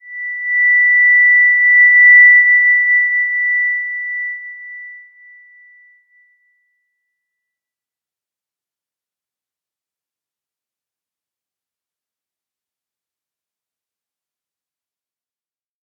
Slow-Distant-Chime-B6-p.wav